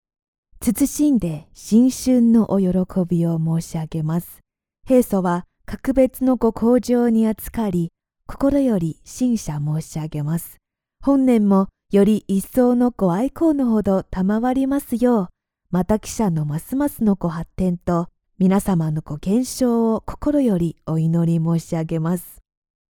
新年祝福-温柔女声